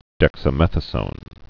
(dĕksə-mĕthə-sōn, -zōn)